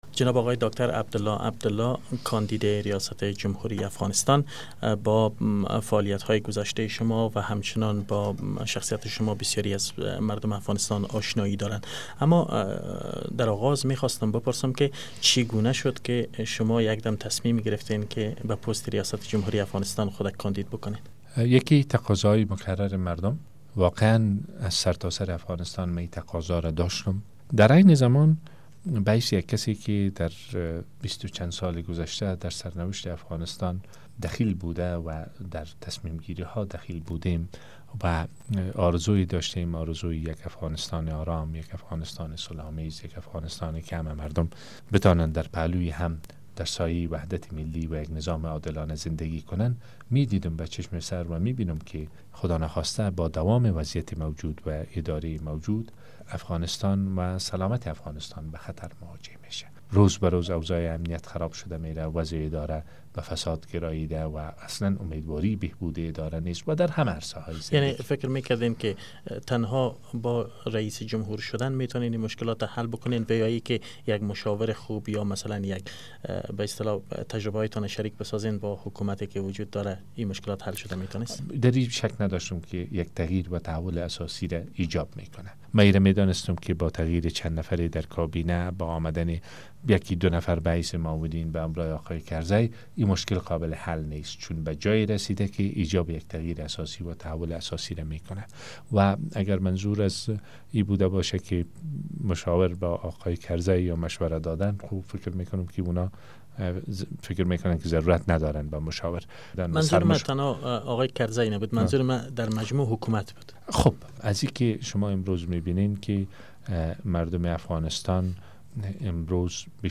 مصاحبه با عبدالله عبدالله